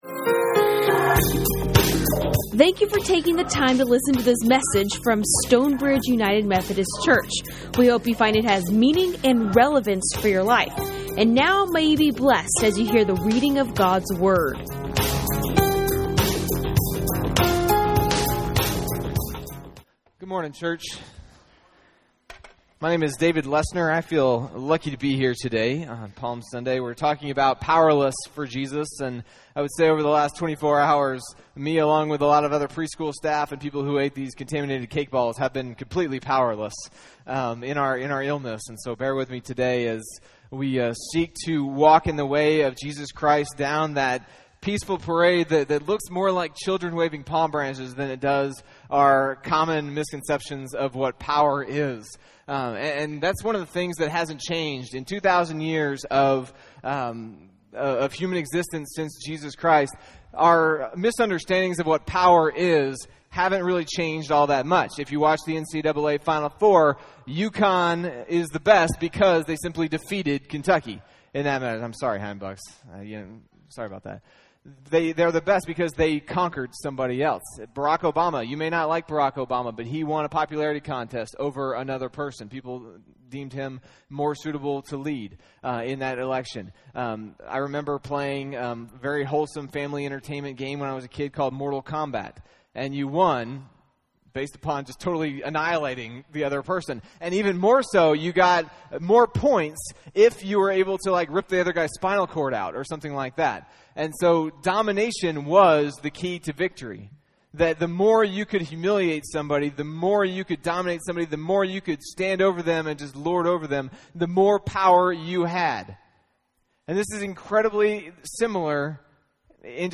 Recorded live at Stonebridge United Methodist Church in McKinney, TX.